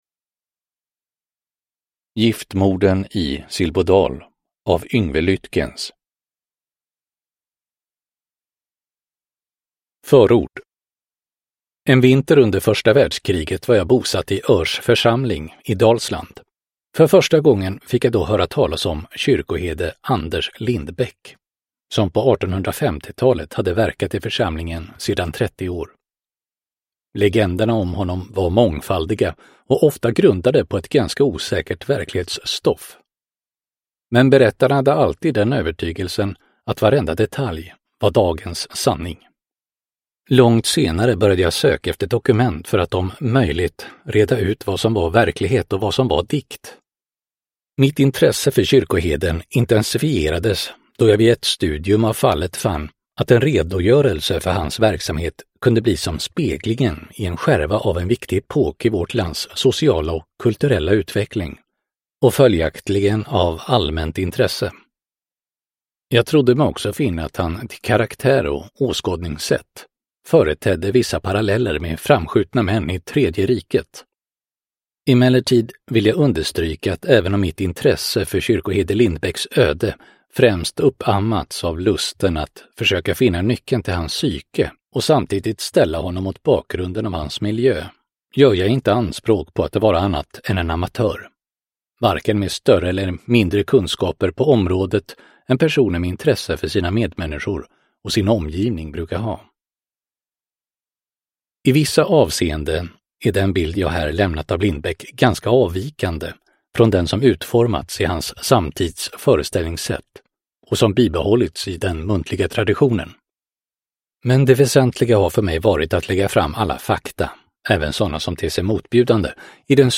Giftmorden i Silbodal : Historiska mord del 1 – Ljudbok – Laddas ner